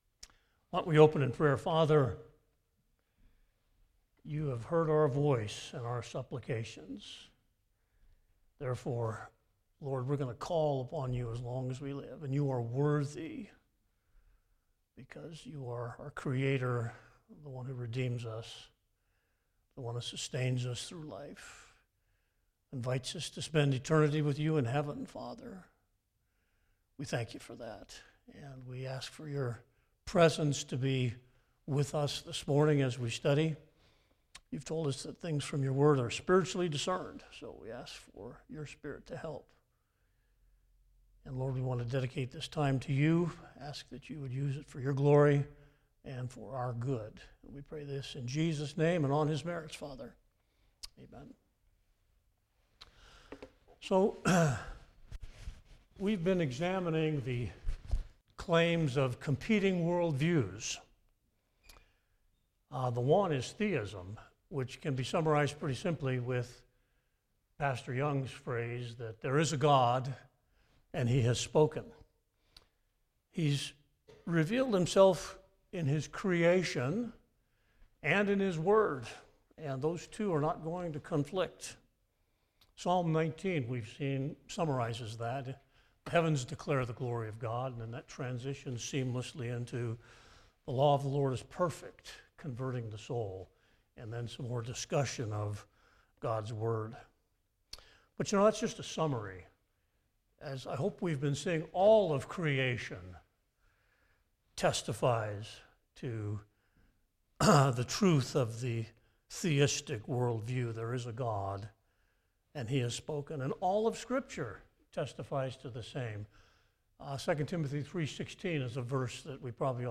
Lesson 11 (Sunday School)